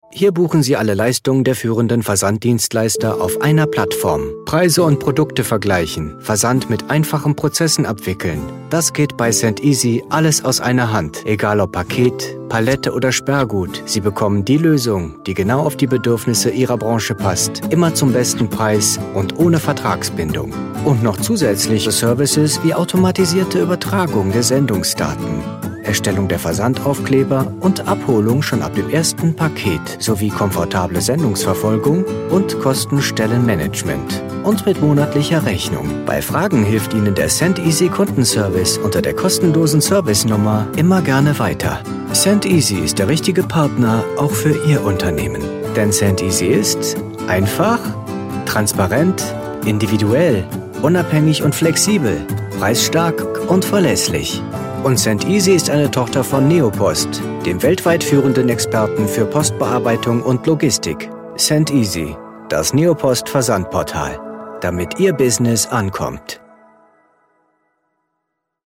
Er trägt mit seiner freundlichen Stimme dazu bei, dass Ihr Produkt, Ihr Projekt an Bedeutung gewinnt und einzigartig wird, ganz nach Ihren Wünschen.
Sprechprobe: Werbung (Muttersprache):